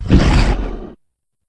c_anusibath_hit2.wav